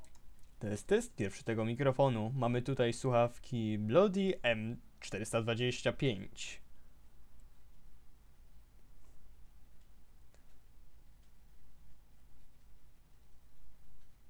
Mikrofon
Jest na akceptowalnym poziomie, do rozmów na TS-ie czy Discordzie i/lub do komunikacji w grach.
Sami zobaczcie, jak brzmi głos przez ten mikrofon – pierwsze nagranie jest bez żadnych efektów (dodatkowo próbka ciszy), a w drugim nałożyłem moje standardowe efekty:
M425-raw.wav